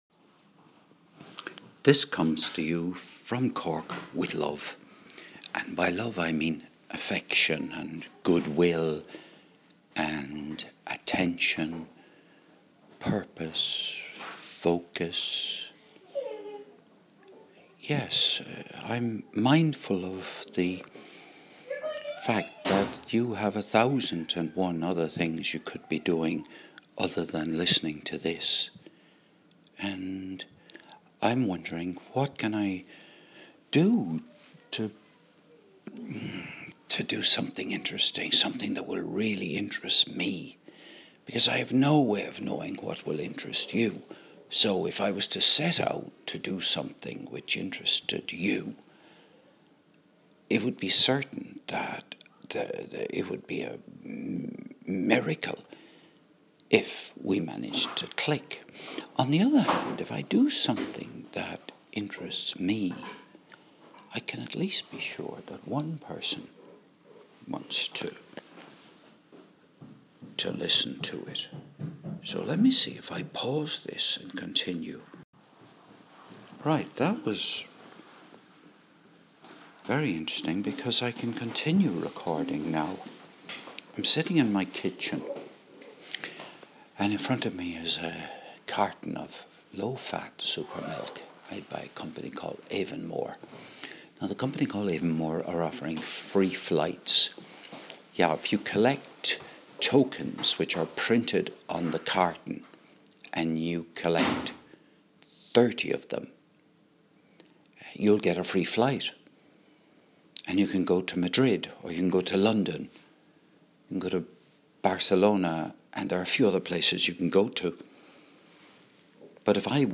Recorded over a cup of big-standard tea (Lyons).